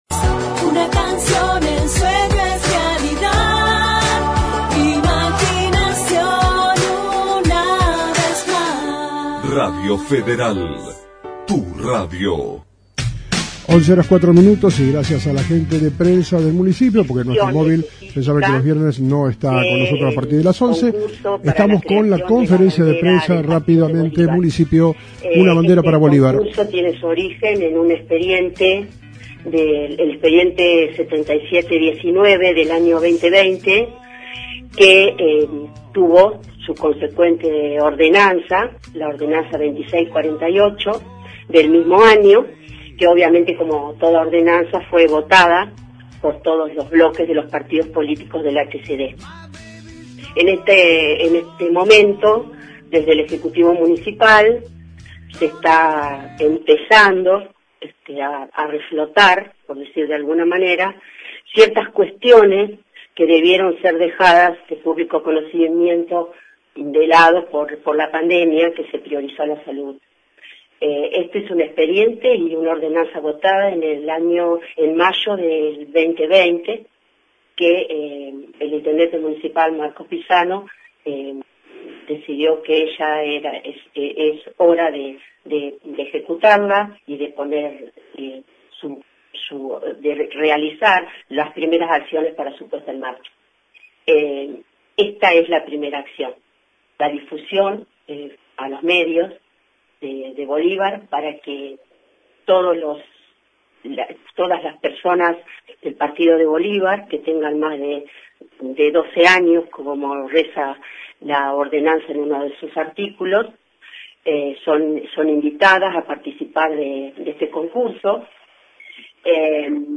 Conferncia de Prensa